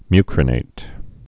(mykrə-nāt)